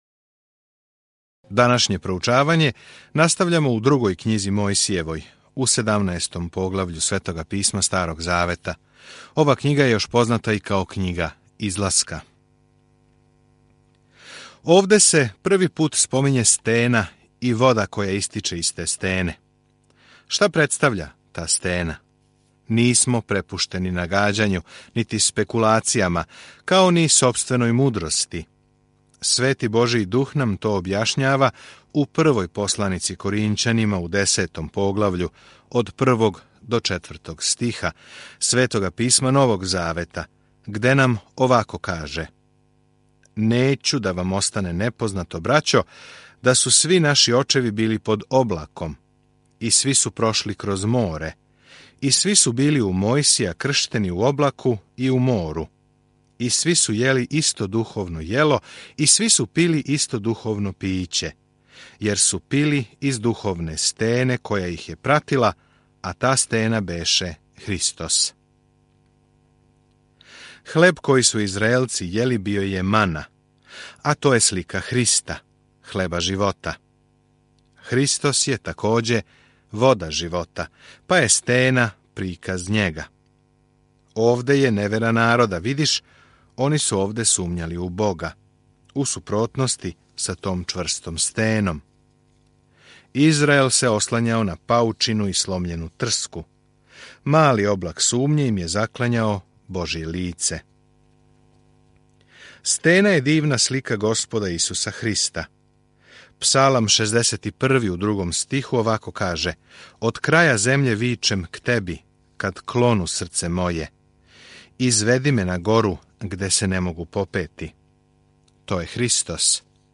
Mojsijeva 18:1-18 Dan 15 Započni ovaj plan Dan 17 O ovom planu Излазак прати бекство Израела из ропства у Египту и описује све што се догодило на том путу. Свакодневно путујте кроз Излазак док слушате аудио студију и читате одабране стихове из Божје речи.